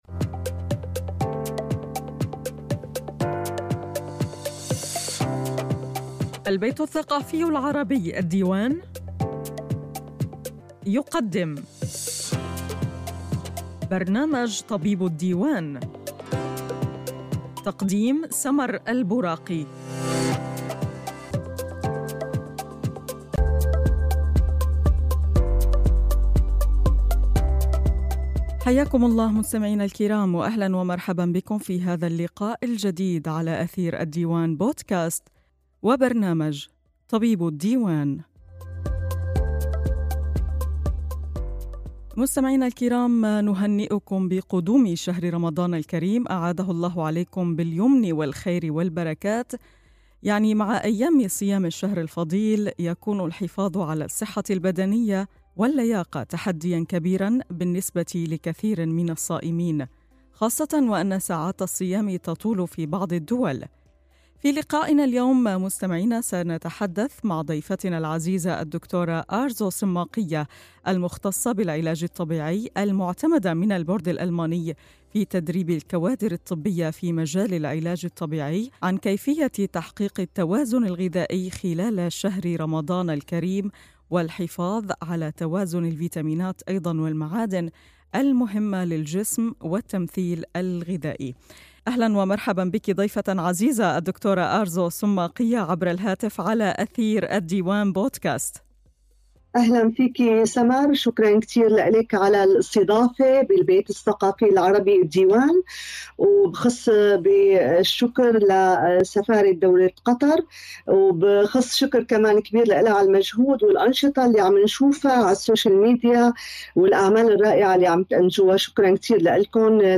Interessante und nützliche Tipps sollen zu verschiedenen medizinischen Themen besprochen werden. In dieser Podcast-Reihe werden Ärzte aus den unterschiedlichen Fachrichtungen bei wöchentlichen Treffen interviewt.